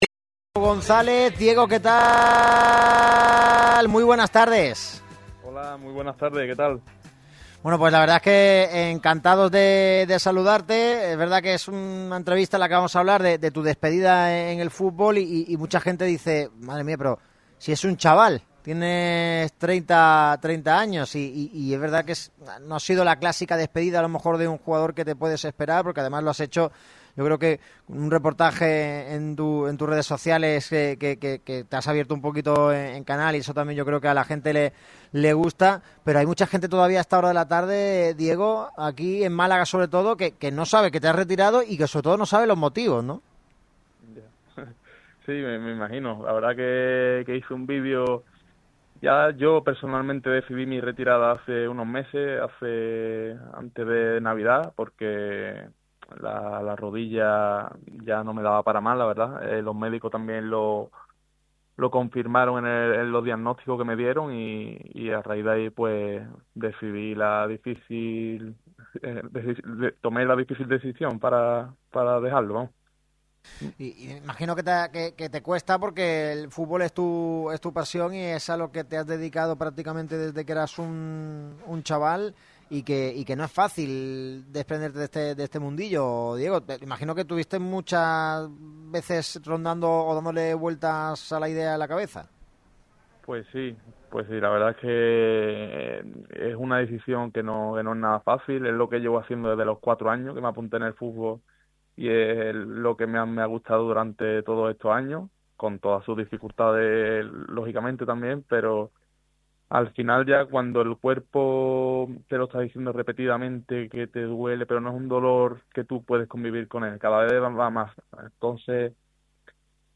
La interesantísima entrevista realizada al exmalaguista.